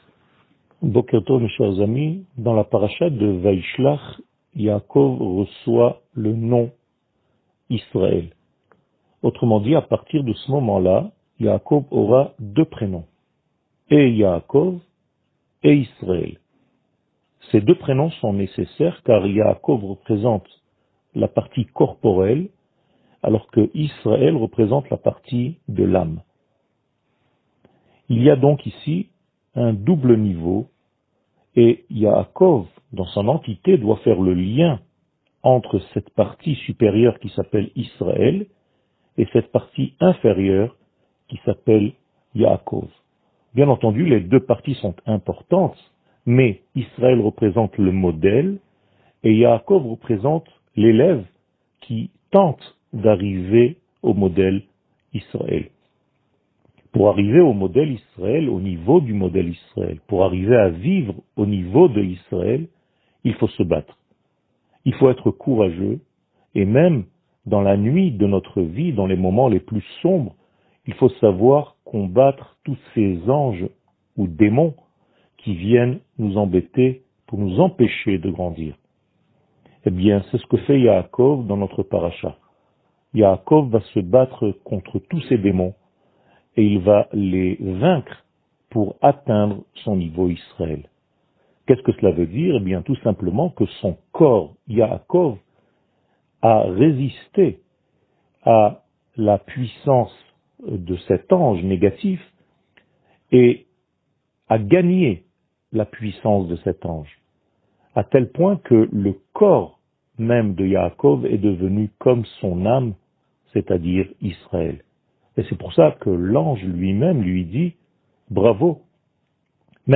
שיעור מ 01 דצמבר 2020